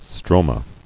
[stro·ma]